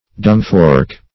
Dungfork \Dung"fork`\, n. A fork for tossing dung.